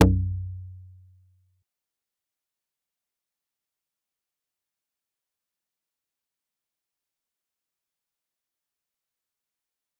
G_Kalimba-B1-pp.wav